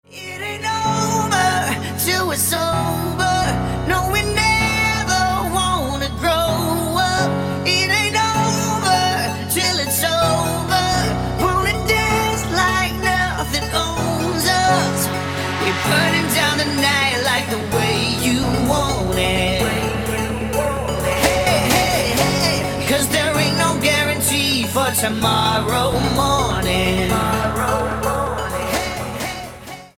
поп
мужской вокал
vocal